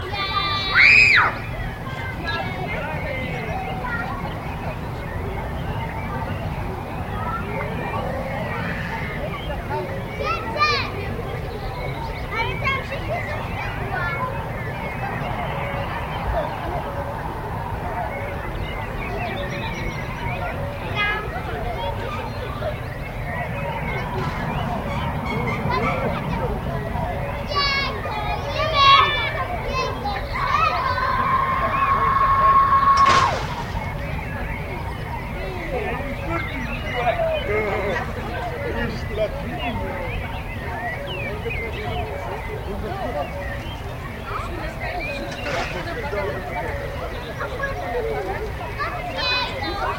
Звуки гриля
Атмосферный звук вечеринки с барбекю во дворе обычной американской семьи